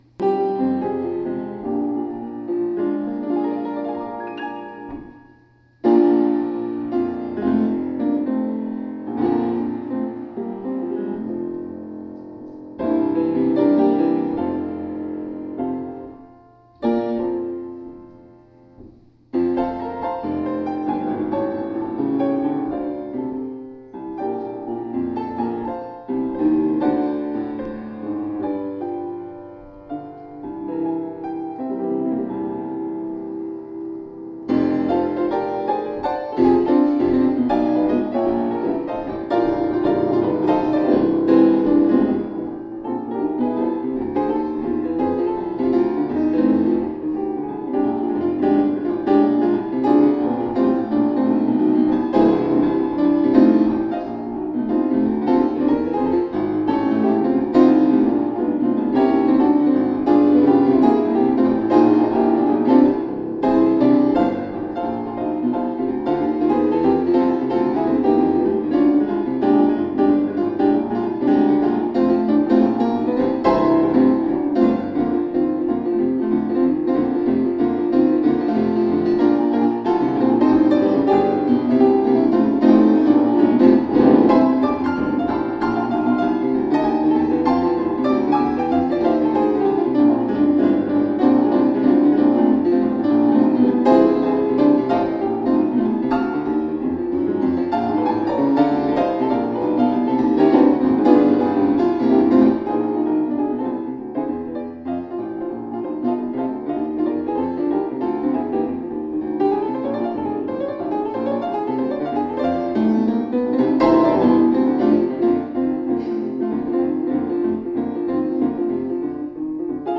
This take had a little extra jam thrown in.